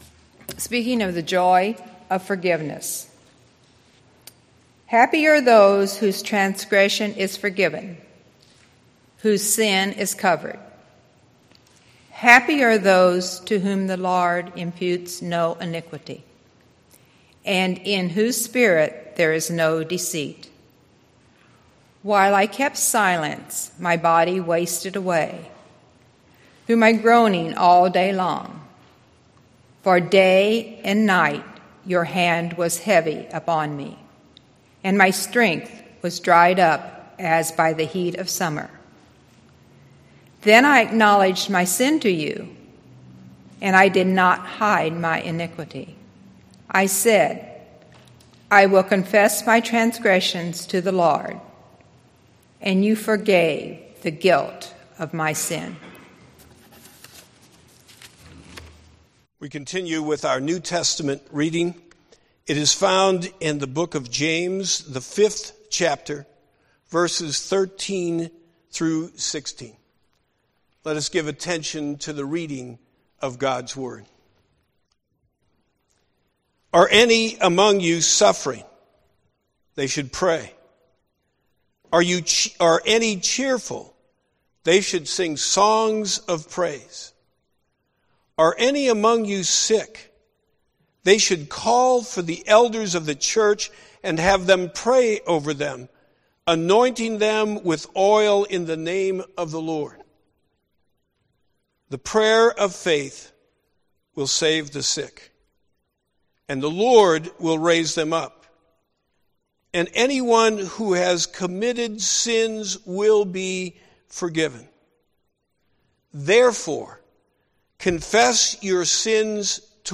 Sermon Podcast – March 3, 2013 – When We Confess Our Sins